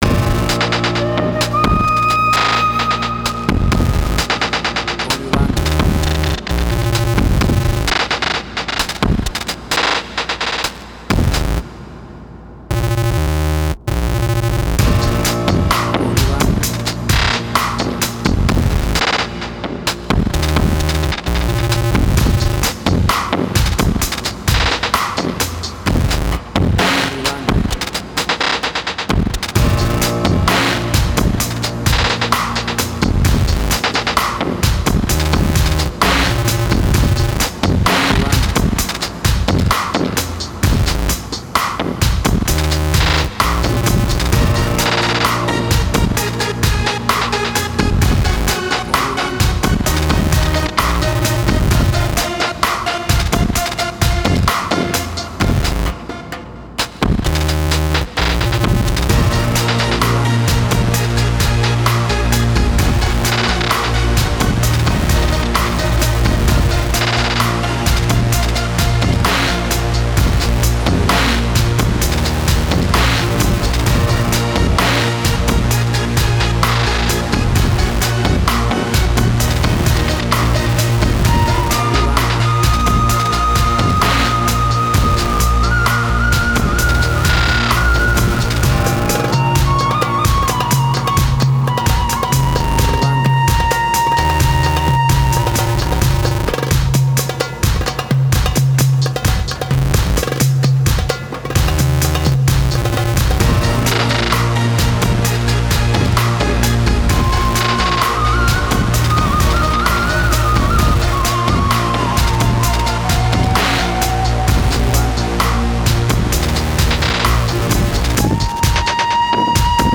Trip Hop
Tempo (BPM): 130